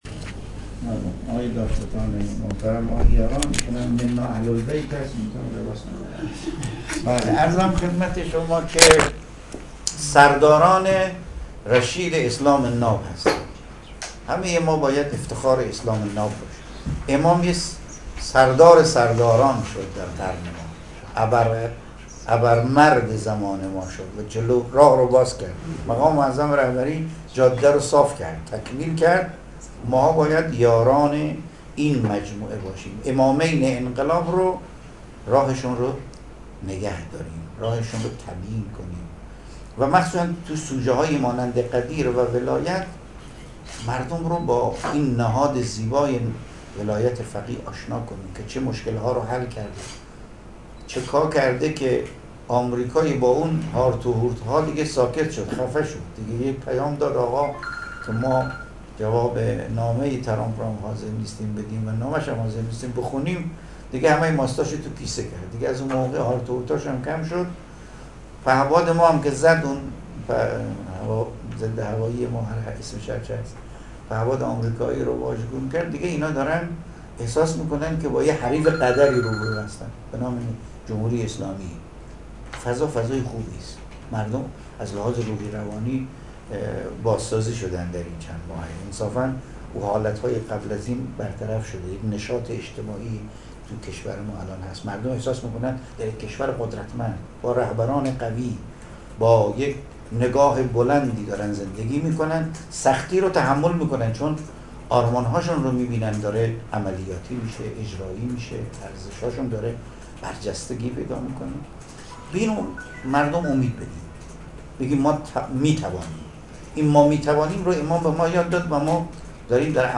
به گزارش خبرنگار خبرگزاری رسا در خراسان شمالی، حجت‌الاسلام والمسلمین ابوالقاسم یعقوبی، نماینده ولی فقیه در خراسان شمالی و امام جمعه بجنورد، امروز در دیدار روحانیان مبلغ غدیر، با گرامیداشت فرا رسیدن دهه امامت و ولایت، به تبیین رسالت روحانیان در این ایام پرداخت و خطاب به آن‌ها اظهار کرد: شما سرداران رشید اسلام ناب هستید و باید افتخار اسلام باشید.